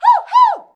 HUH-HUH.wav